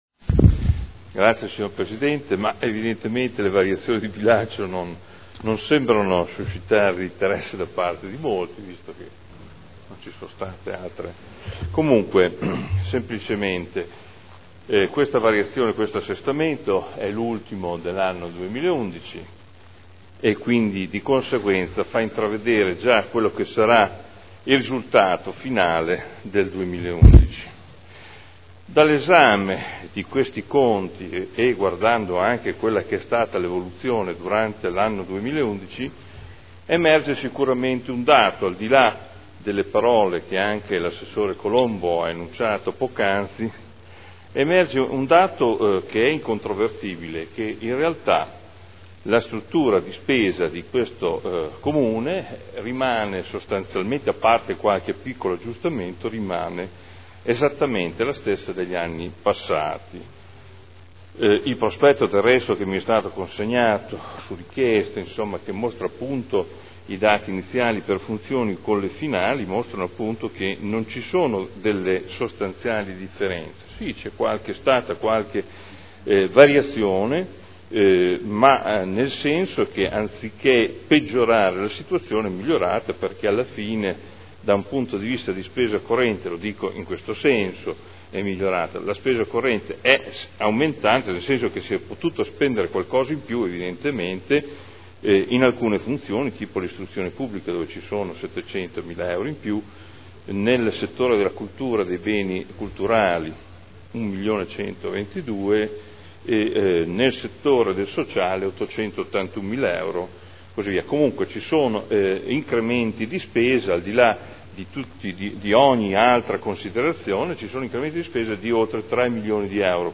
Adolfo Morandi — Sito Audio Consiglio Comunale
Seduta del 28 novembre Proposta di deliberazione Bilancio di previsione 2011 - Bilancio pluriennale 2011-2013 - Programma triennale dei lavori pubblici 2011-2013 - Assestamento - Variazione di bilancio n. 3 Dibattito